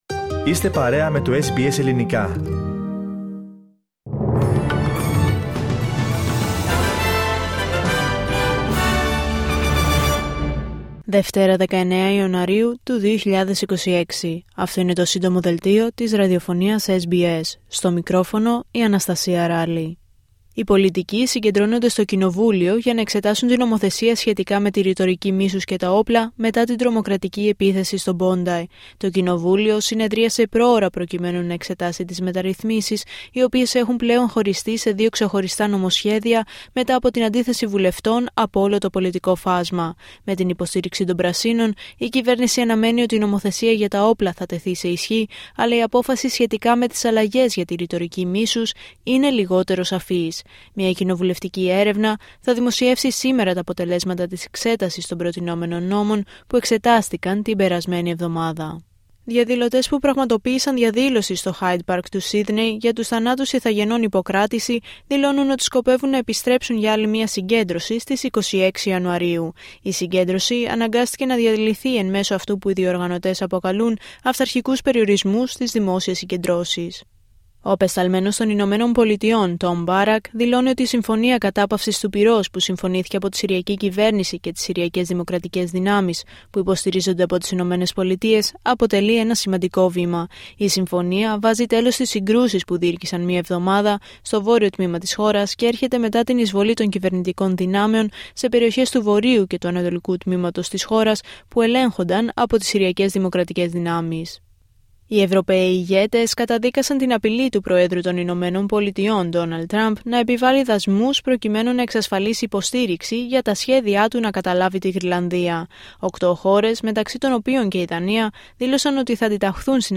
H επικαιρότητα έως αυτή την ώρα στην Αυστραλία, την Ελλάδα, την Κύπρο και τον κόσμο στο Σύντομο Δελτίο Ειδήσεων της Δευτέρας 19 Ιανουαρίου 2026.